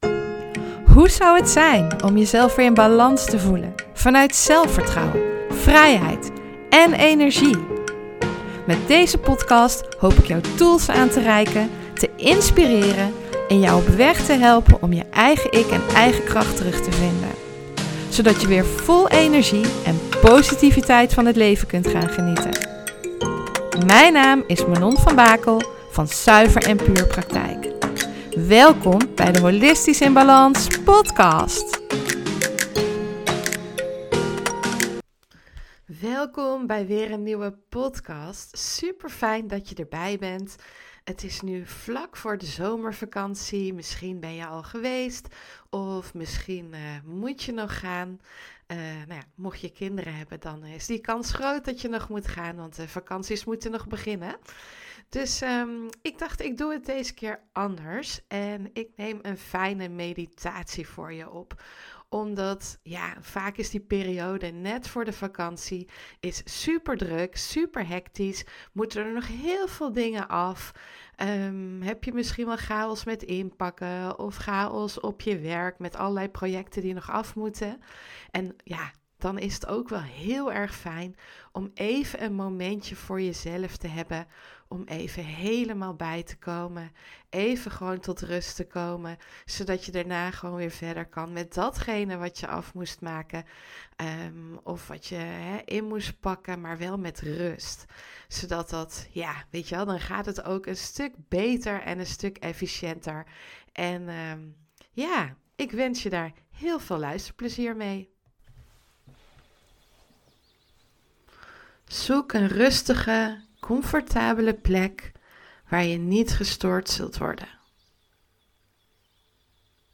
Daarom heb ik deze meditatie voor je opgenomen. Zodat je even tot rust kan komen tussen de hectiek door.